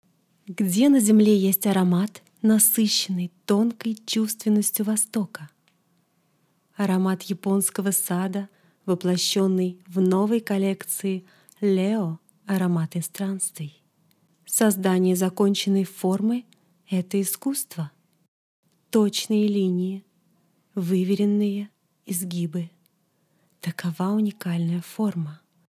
Начитка текста для рекламы
Дикция четкая.